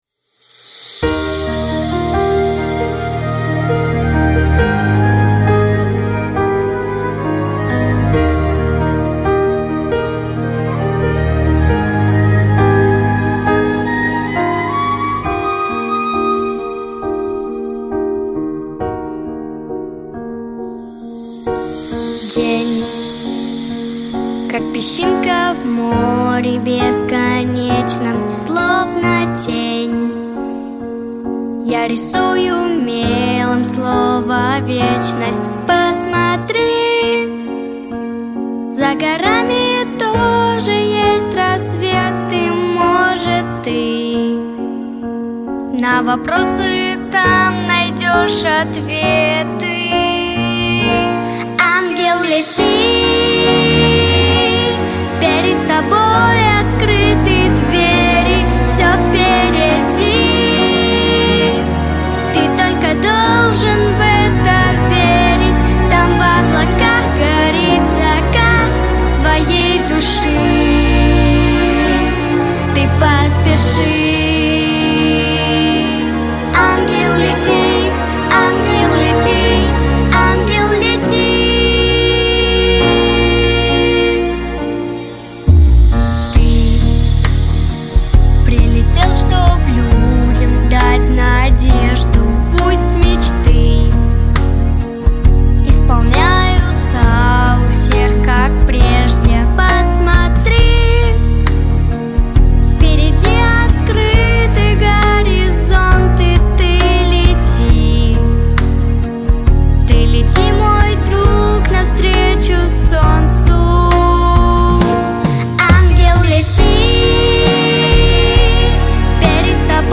• Качество: Хорошее
• Жанр: Детские песни
христианские песни